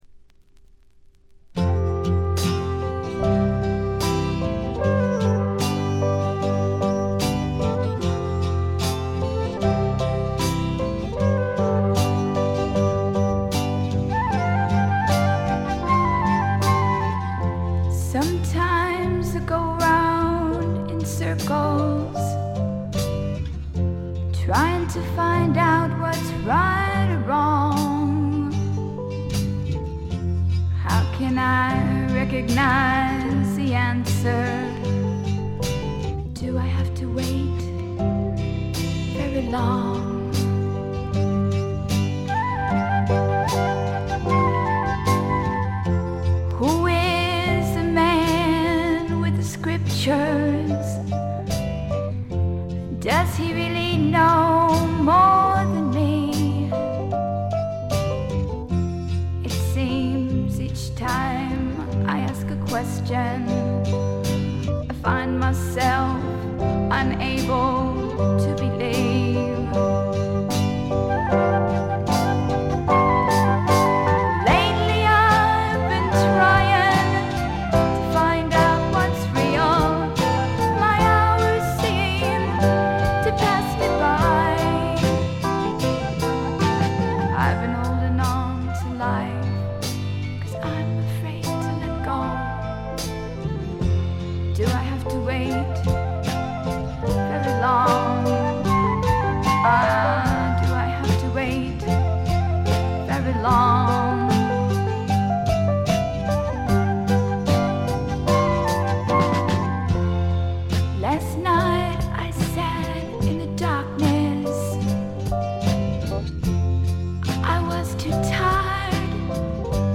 全曲自作の素晴らしい楽曲、清楚な歌声、美しいアコギの音色、60年代気分を残しているバックの演奏、たなびくフルートの音色。
試聴曲は現品からの取り込み音源です。